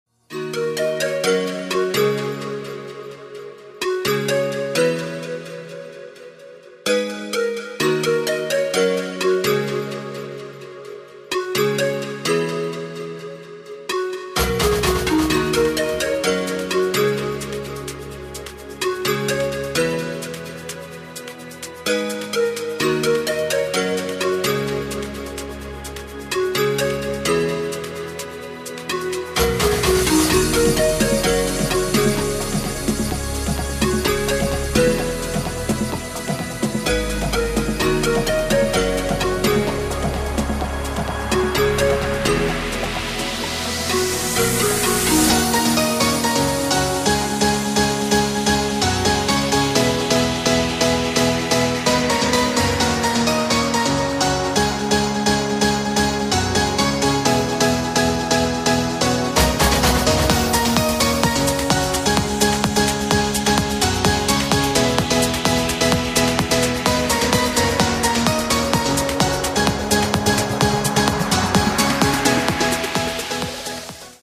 • Качество: 128, Stereo
мелодичные
Electronic
EDM
электронная музыка
без слов
нарастающие
клавишные
progressive house